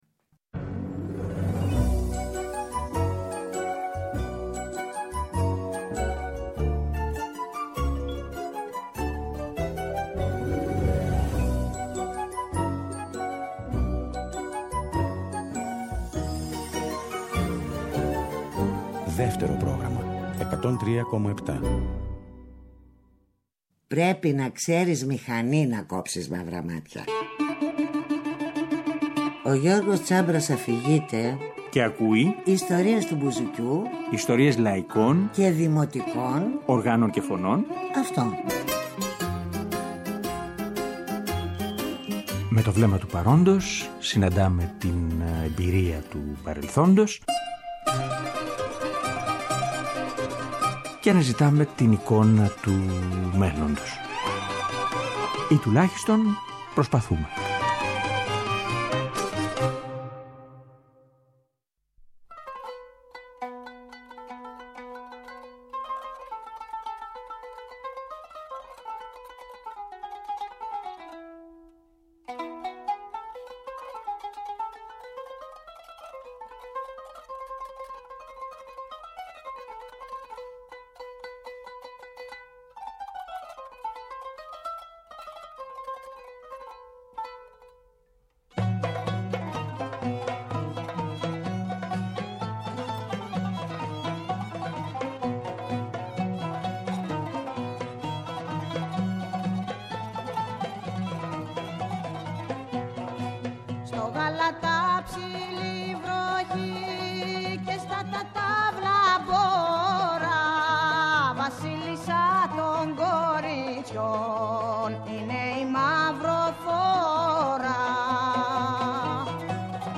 Δευτέρα 28 Δεκεμβρίου, 9 με 10 το βράδυ, στο Δεύτερο Πρόγραμμα 103.7. Επειδή τέτοιες μέρες, θέλουμε να επιστρέφουμε στην μαγεία του παλιού ήχου, των ιστοριών άλλων εποχών. Ο δίσκος ηχογραφήθηκε μέσα σε τρείς μέρες, λίγο μετά το Πολυτεχνείο – Νοέμβρη του 1973.